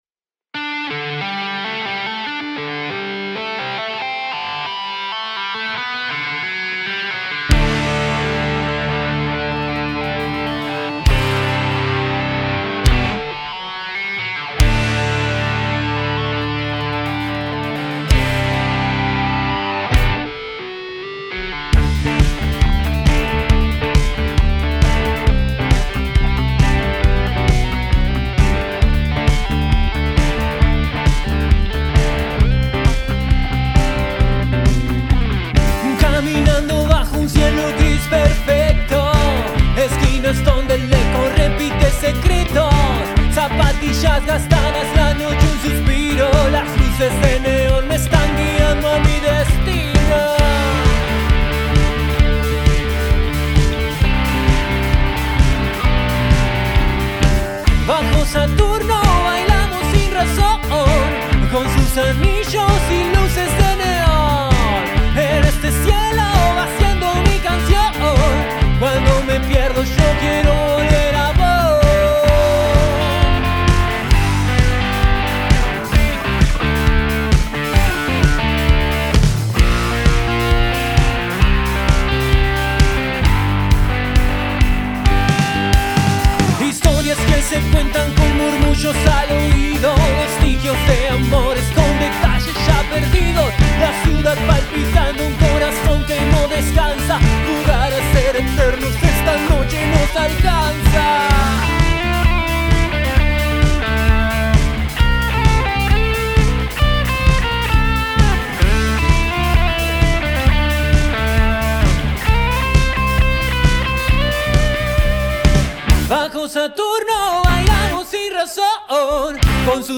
Grabada en vivo el 1 de junio de 2025